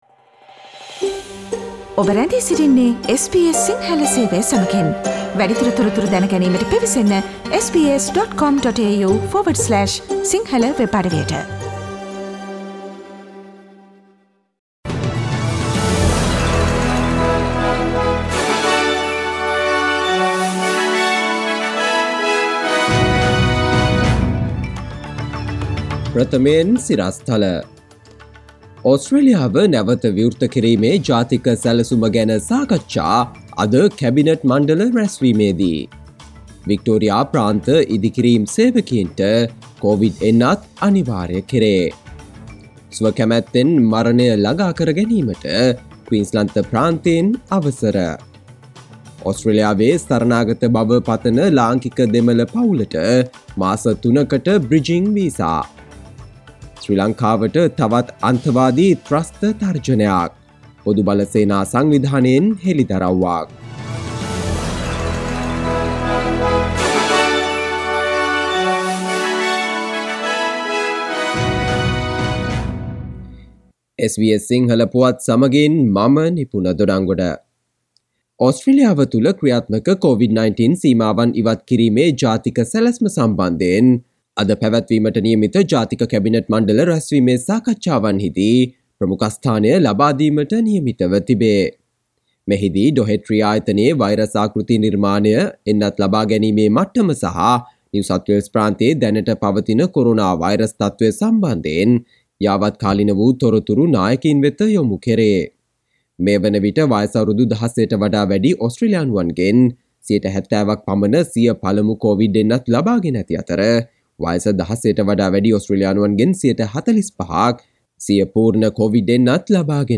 සවන්දෙන්න 2021 සැප්තැම්බර්17 වන සිකුරාදා SBS සිංහල ගුවන්විදුලියේ ප්‍රවෘත්ති ප්‍රකාශයට...